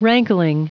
Prononciation du mot rankling en anglais (fichier audio)
Prononciation du mot : rankling